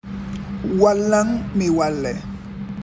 hear pronunciation) (WMW), meaning “Help Me, I Help You” in Fulani, is a mutual aid initiative that supports recent West African migrants as they rebuild their lives in New York City.